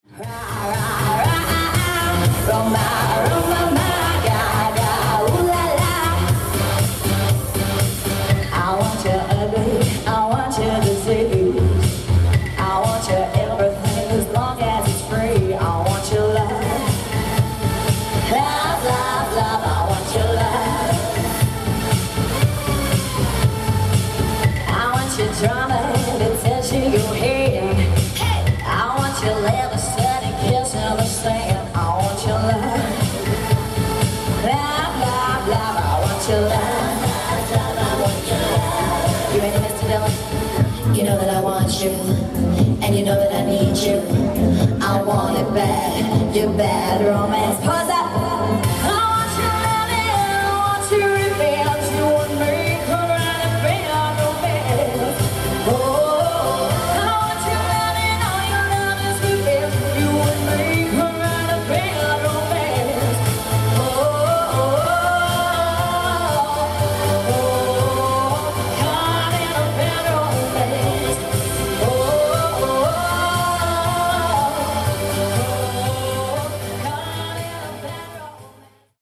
With pitch perfect vocals